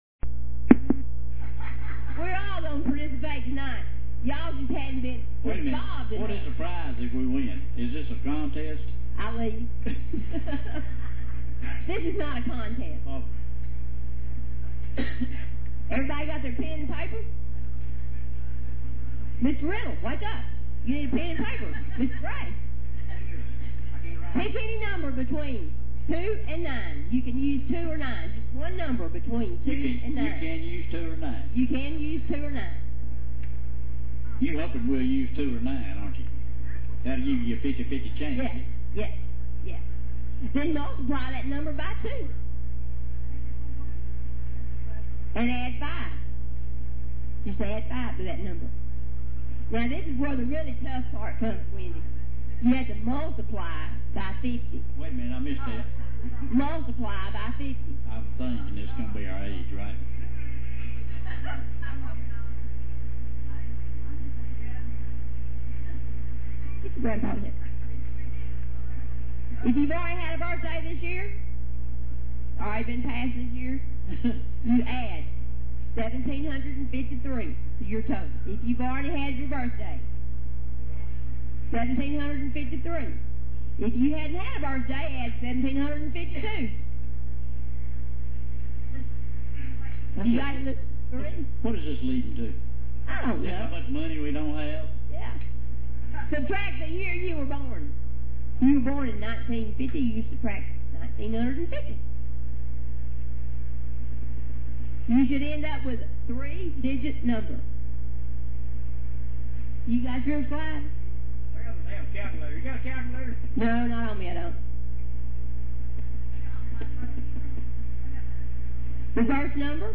VFD Firehouse Jamboree Story!